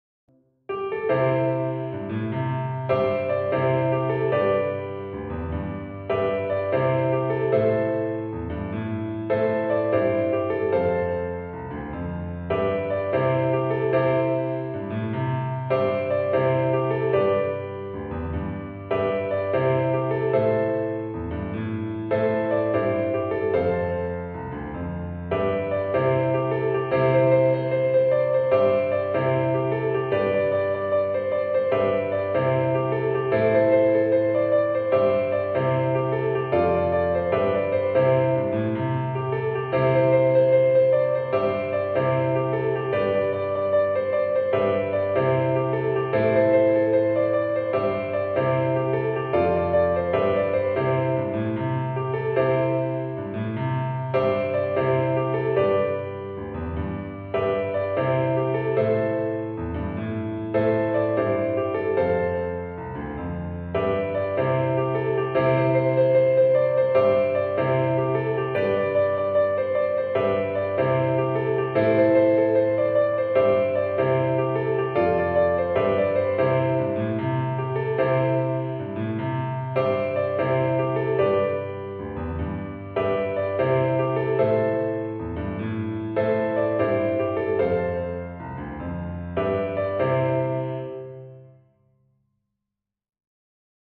for piano